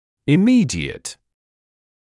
[ɪ’miːdɪət][и’миːдиэт]незамедлительный, моментальный, происходящий сразу же